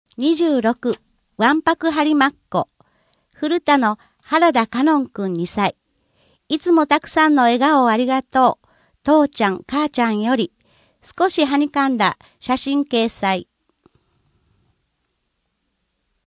声の「広報はりま」5月号
声の「広報はりま」はボランティアグループ「のぎく」のご協力により作成されています。